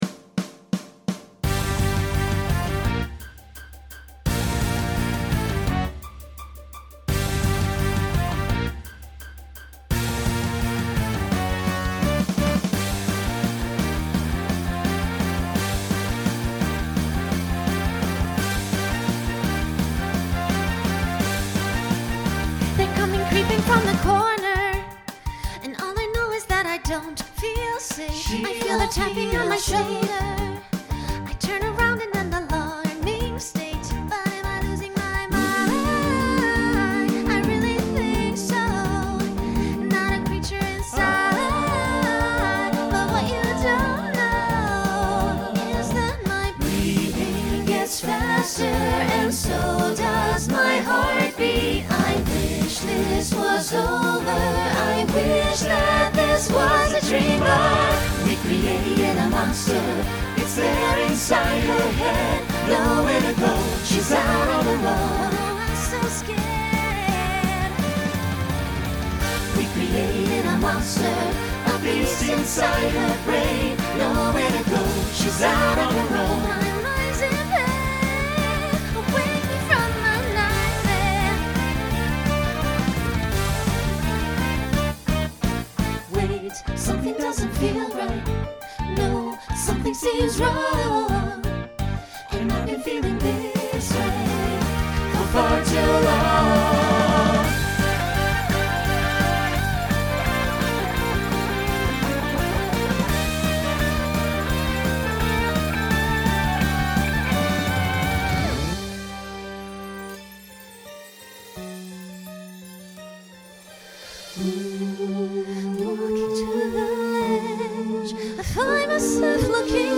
Genre Rock Instrumental combo
Story/Theme Voicing SATB